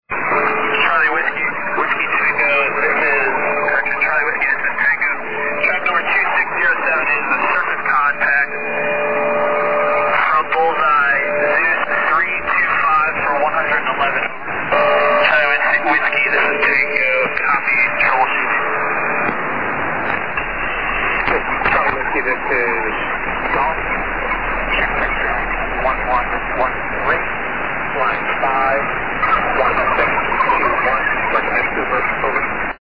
CW ths T